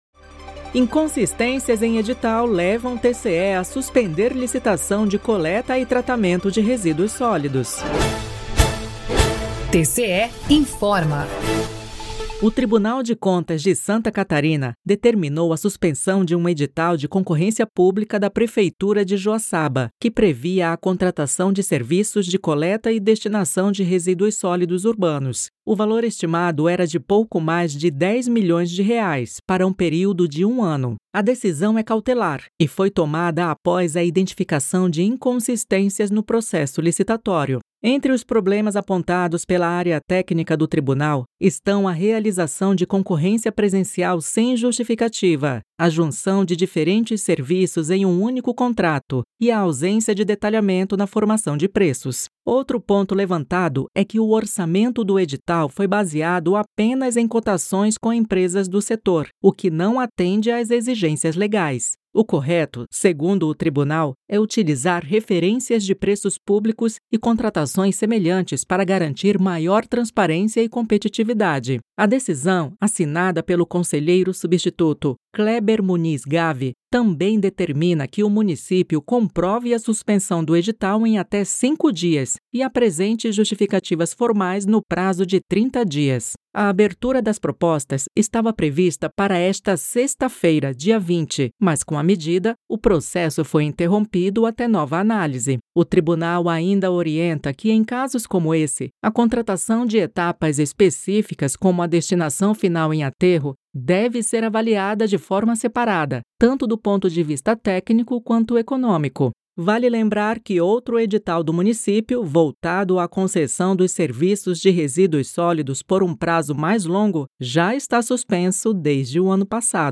VINHETA TCE INFORMA
VINHETA TCE INFORMOU